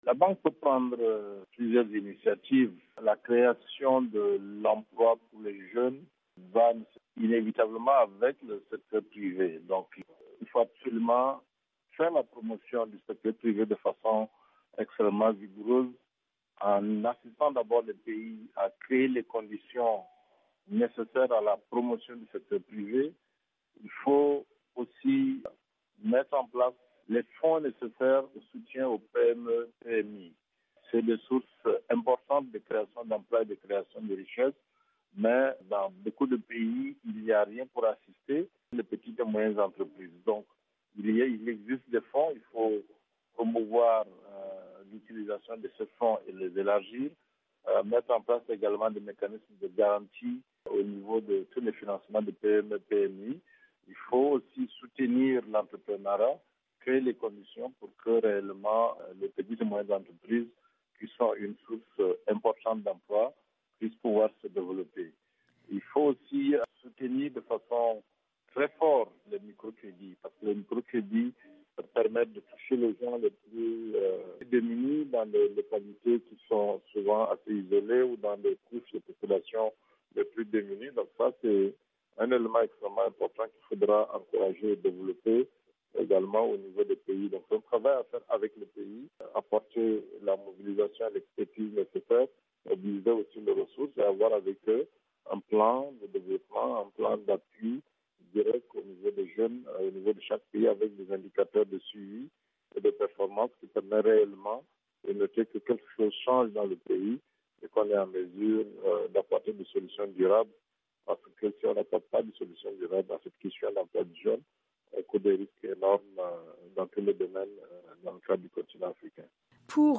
Ecoutez le ministre tchadien des Finances et du Budget Kordje Bedoumra .mp3
"La banque peut prendre plusieurs initiatives", a-t-il déclaré sur VOA Afrique.